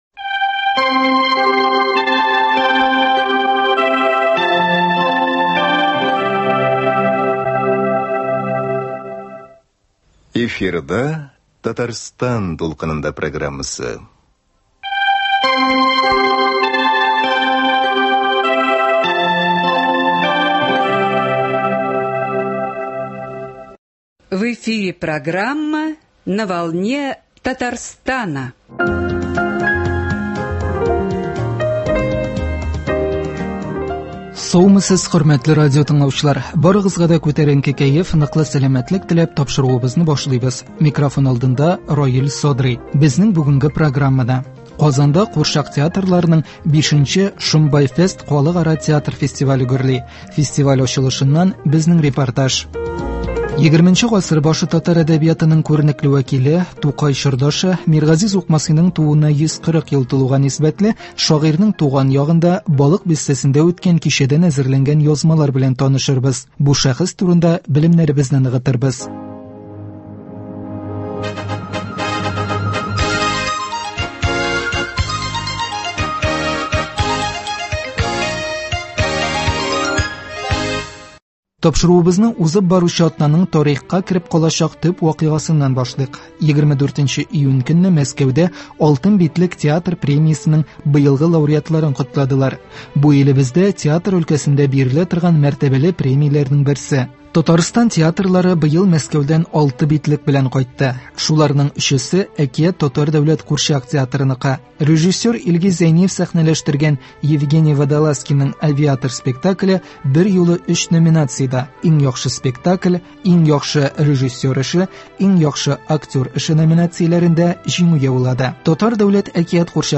Безнең бүгенге программада: Казанда курчак театрларының 5 нче “Шомбай фест” Халыкара театр фестивале гөрли. Фестиваль ачылышыннан безнең репортаж.